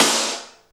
51.03 SNR.wav